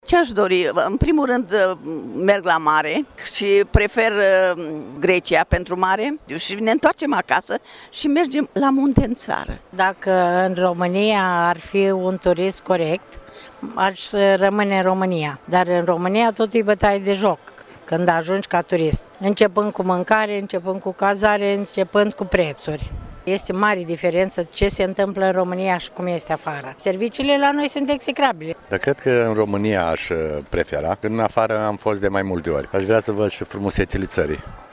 18-martie-ora-14-vox-pop.mp3